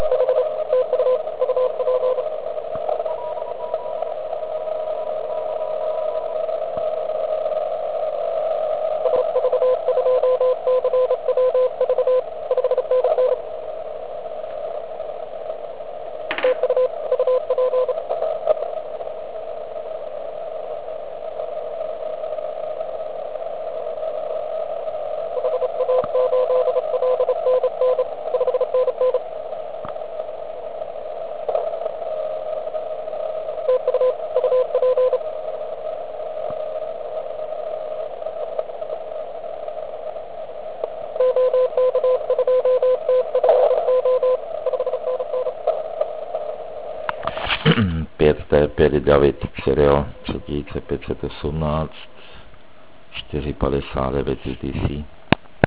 Mysl�m, �e nav�zat spojen� pro OK stanice skute�n� nen� probl�m. Koneckonc� "sb�r�n�" zemi�ek p�i dne�n�m �pi�kov�m vybaven� expedic je celkem brnka�ka. A jak to bylo sly�et na "p�dlovku"? - samoz�ejm� poslouch�no na ATOSE..